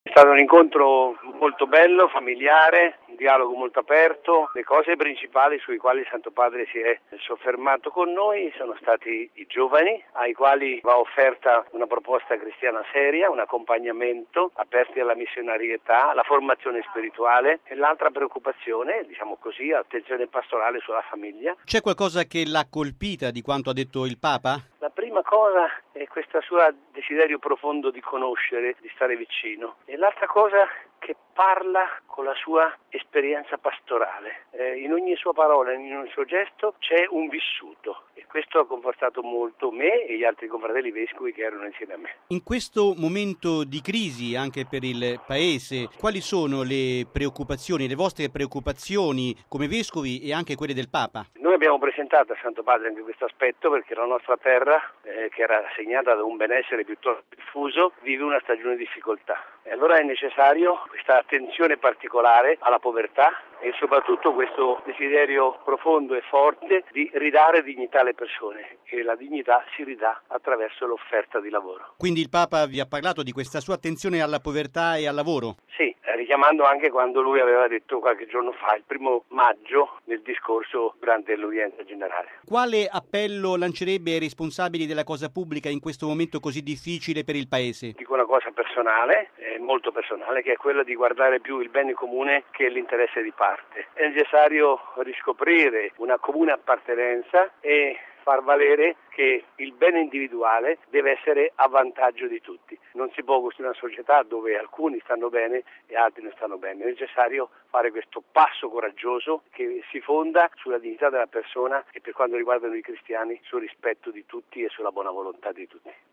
◊   Il Papa ha ricevuto oggi il primo gruppo di vescovi della Conferenza episcopale delle Marche, in visita "ad Limina". Tra di essi c’era anche mons. Edoardo Menichelli, arcivescovo di Ancona-Osimo.